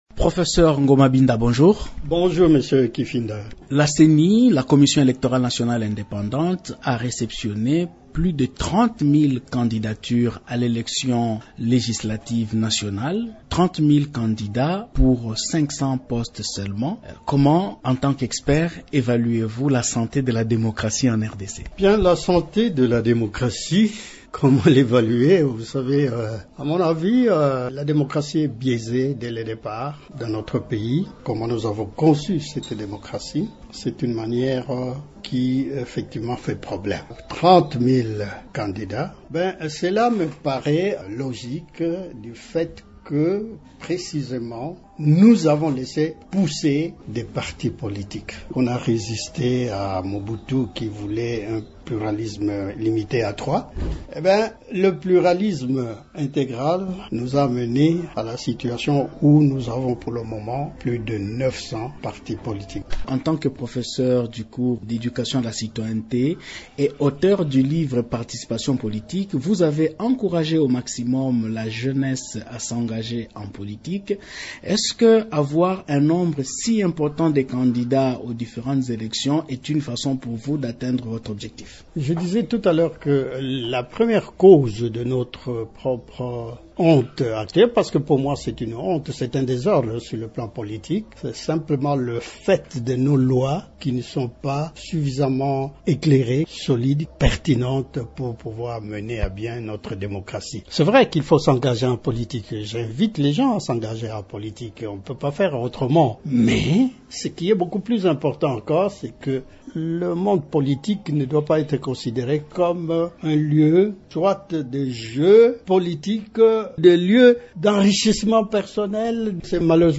Invité de Radio Okapi ce vendredi 6 octobre